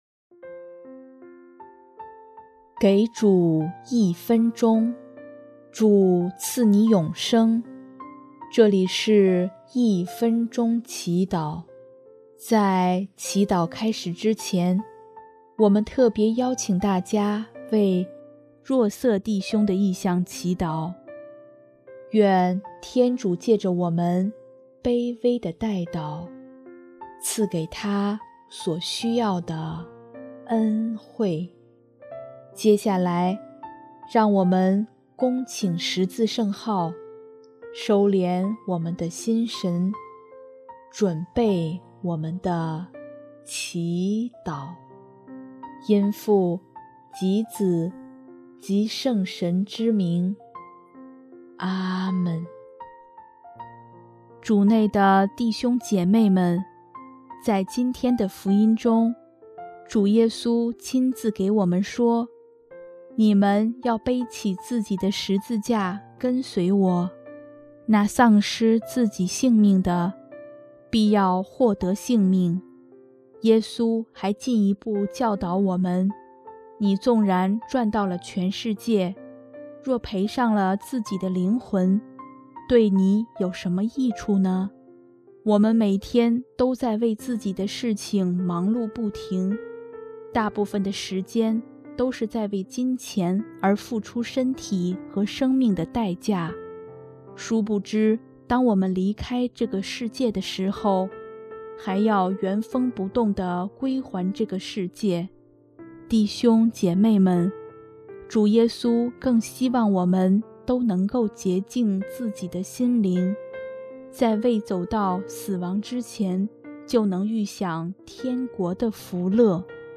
【一分钟祈祷】
音乐： 主日赞歌